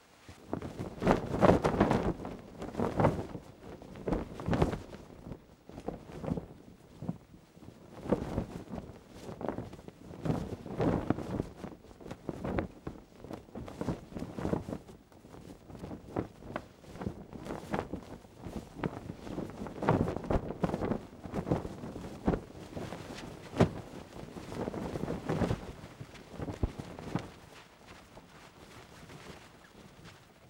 cloth_sail.R.wav